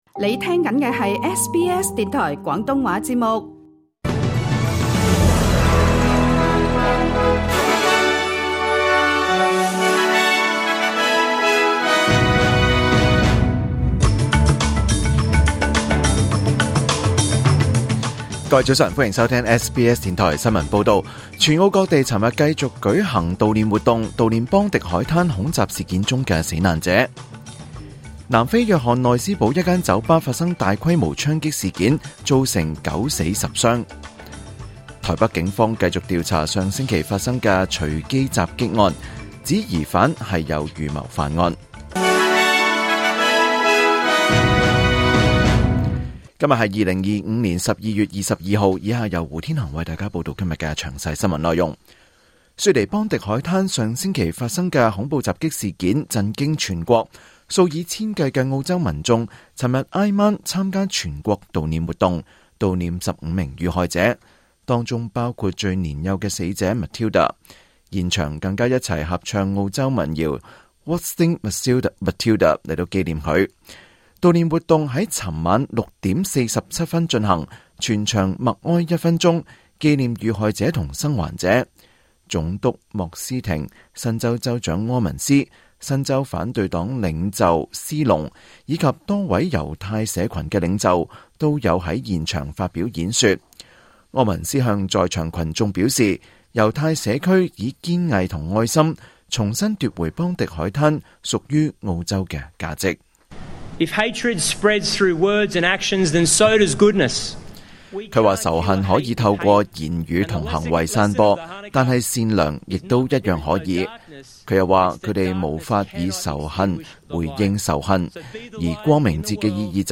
2025年12月22日 SBS 廣東話節目九點半新聞報道。